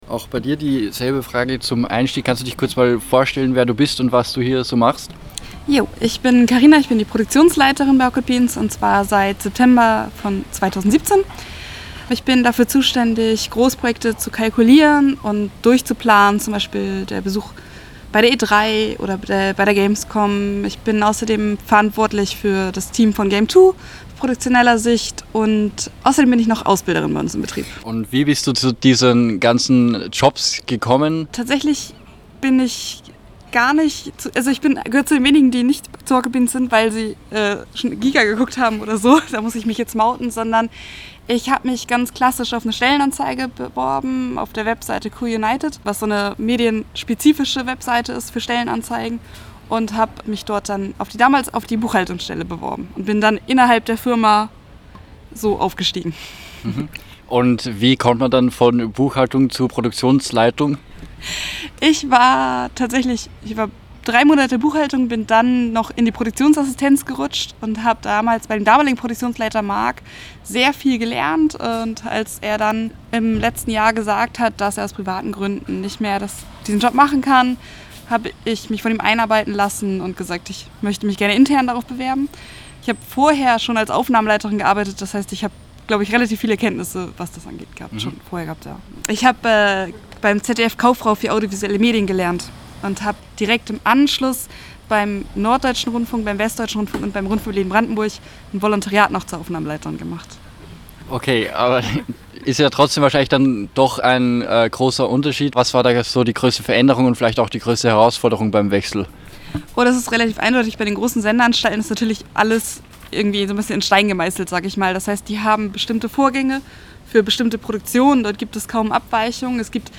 Im Rahmen eines Besuchs beim Livestream-Kanal „Rocketbeans TV“ in Hamburg sprachen wir mit Angestellten vor Ort über die Arbeit in den Medien und ihren Weg dorthin.